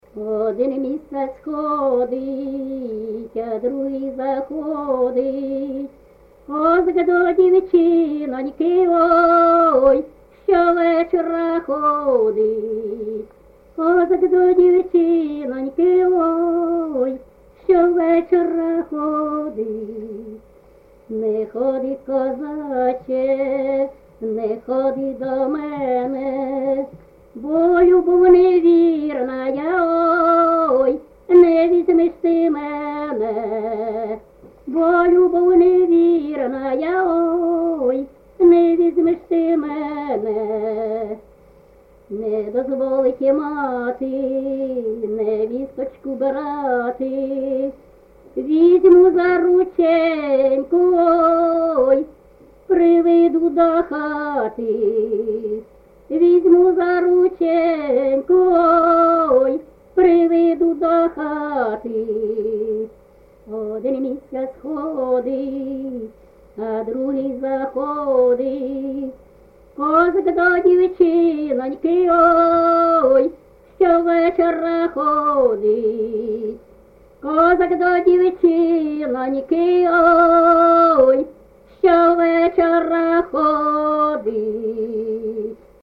ЖанрПісні з особистого та родинного життя, Козацькі
Місце записус-ще Михайлівське, Сумський район, Сумська обл., Україна, Слобожанщина